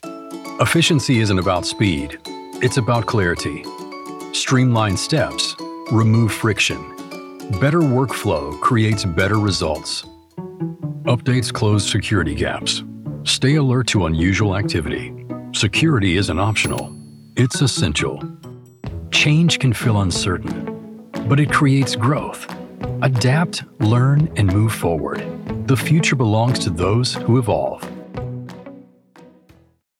Conversational Male Voice-Commercial, Corporate Narration & E-Learning
E-Learning- Clear, engaging instructional tone.